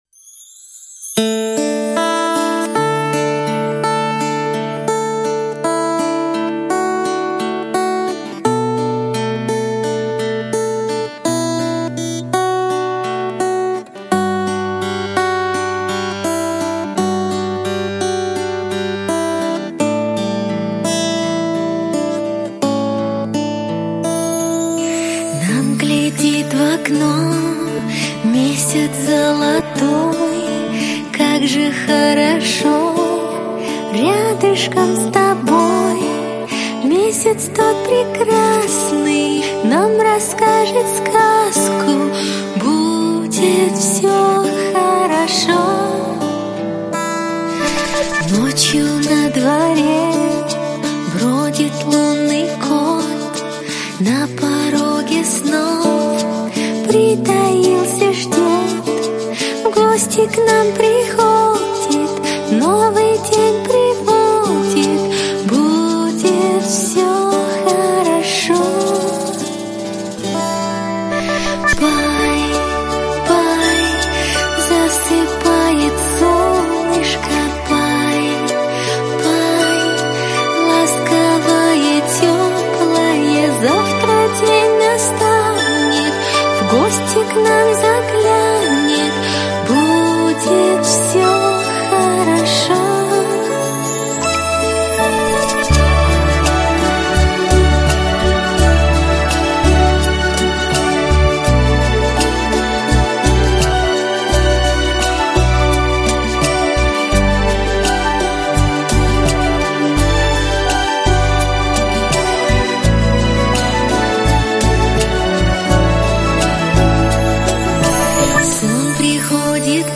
Колыбельные